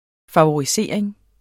Udtale [ fɑwoɐ̯iˈseˀeŋ ]